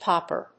音節pop・per 発音記号・読み方
/pάpɚ(米国英語), pˈɔpə(英国英語)/